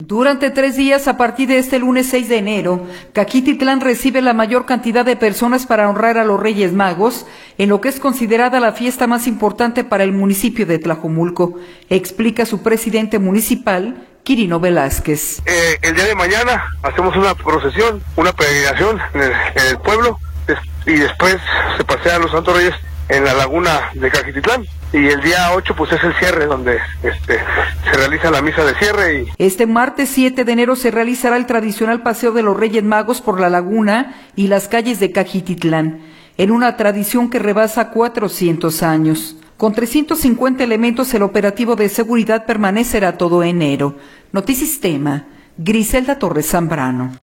Durante tres días a partir de este lunes 06 de enero, Cajititlán recibe la mayor cantidad de personas para honrar a los Reyes Magos, en lo que es considerada la fiesta más importante para el municipio de Tlajomulco, explica su presidente municipal, Quirino Velázquez.